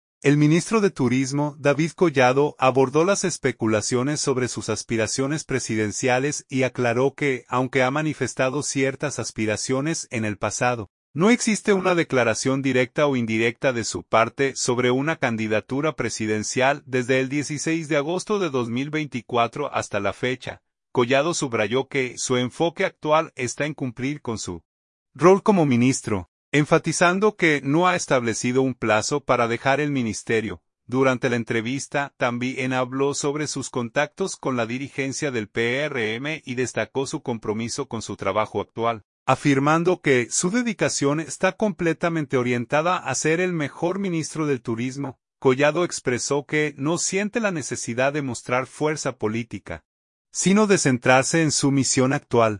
Durante la entrevista, también habló sobre sus contactos con la dirigencia del PRM y destacó su compromiso con su trabajo actual, afirmando que su dedicación está completamente orientada a ser el mejor Ministro del Turismo.